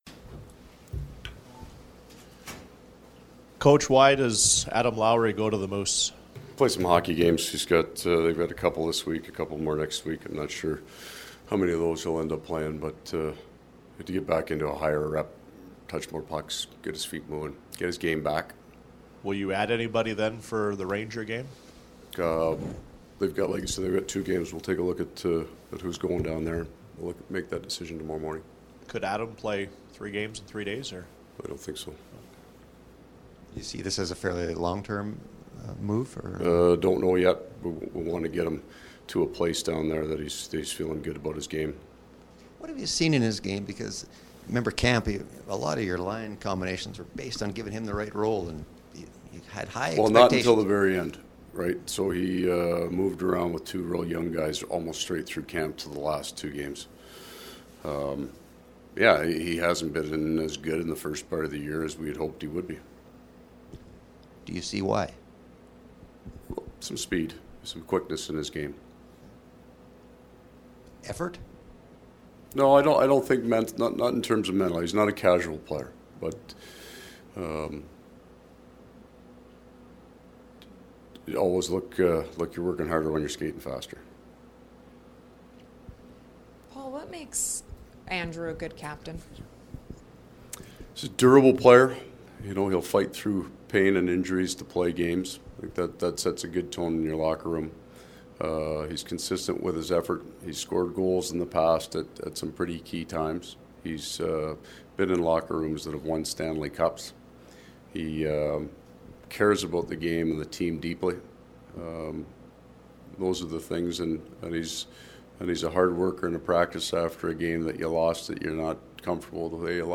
Coach Maurice chatted with media following the skate at MTS Centre.